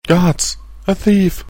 Englische Sprecher (m)
Thief.mp3